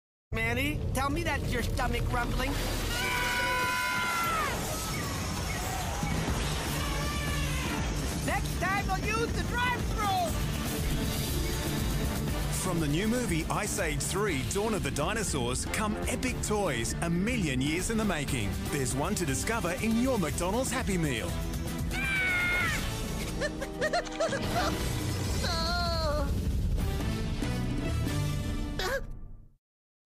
Ice Age 3 Dawn of The Dinosaurs McDonald's Commercial (2009)